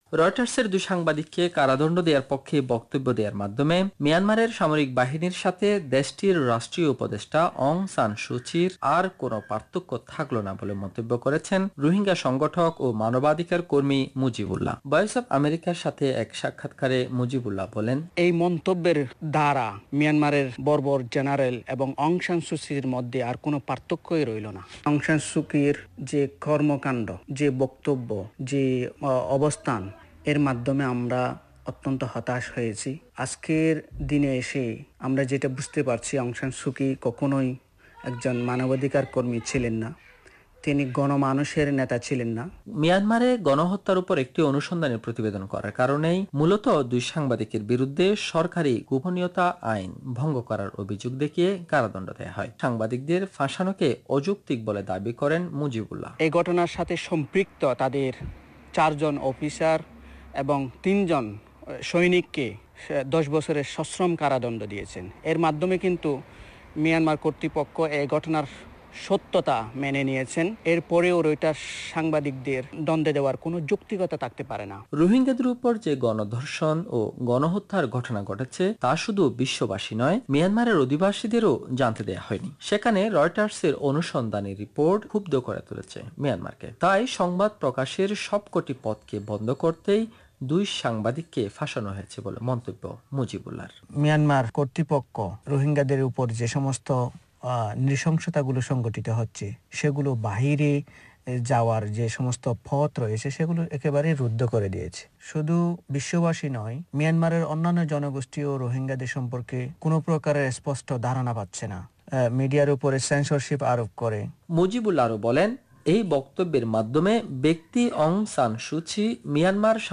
কক্সবাজার থেকে